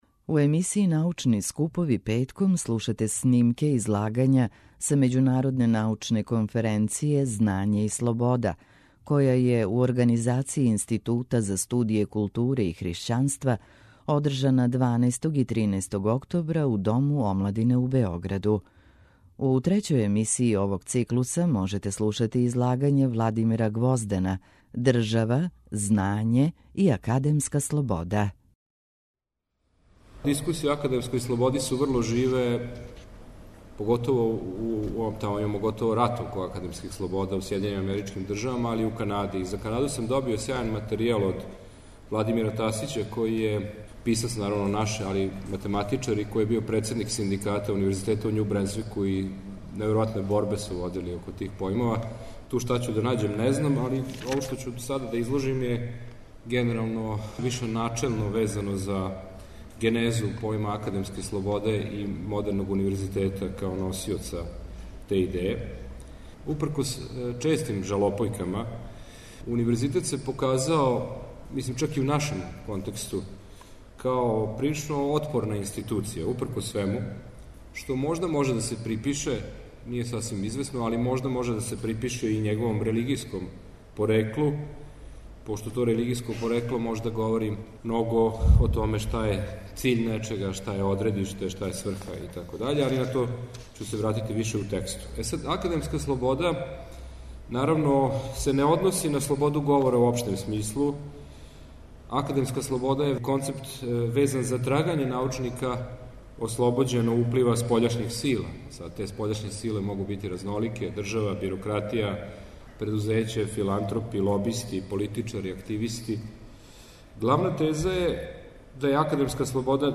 У емисији Научни скупови, петком пратите снимке излагања са међународне научне конференције „Знање и слобода” која је, у организацији Института за студије културе и хришћанства, одржана 12. и 13. октобра у Дому омладине у Београду.